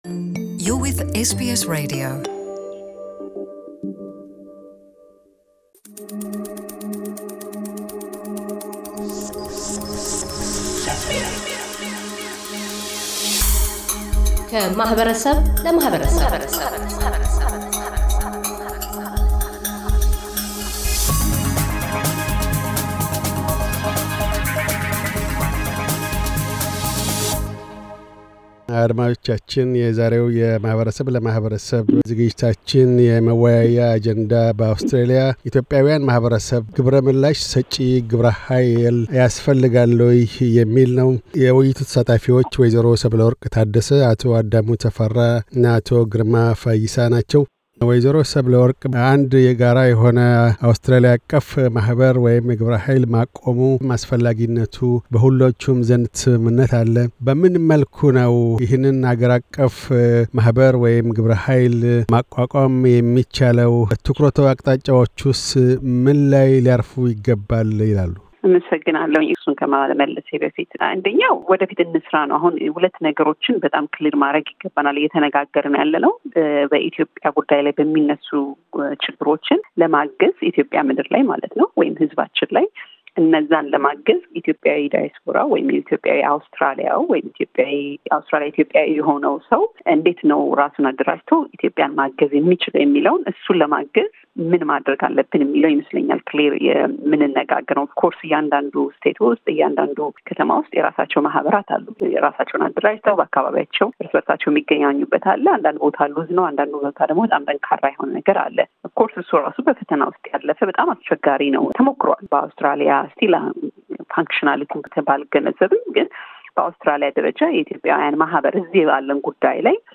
የውይይት መድረኩ ተሣታፊዎች